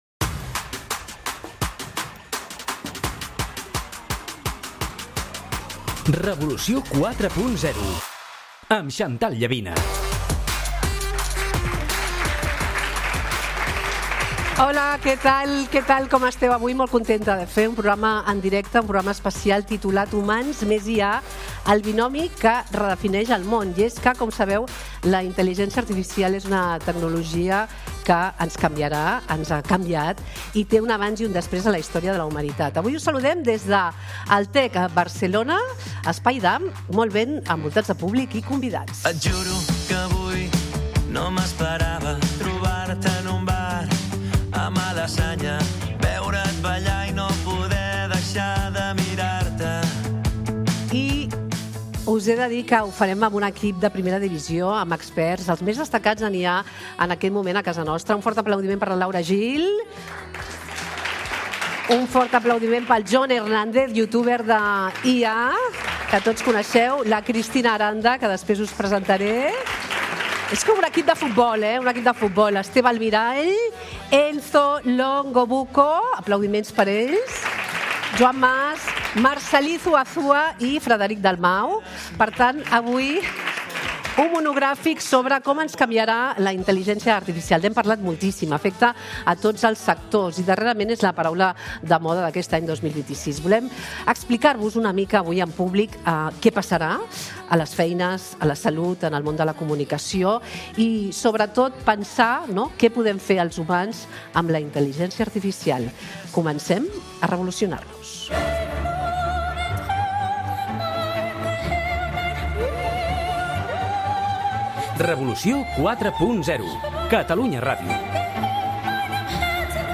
Un monogràfic en directe des del Tech Barcelona Pier 01, a l'espai Damm, amb públic i convidats, que analitza la tecnologia que està transformant la societat.